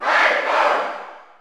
Category: Crowd cheers (SSBU) You cannot overwrite this file.
Falco_Cheer_German_SSBU.ogg.mp3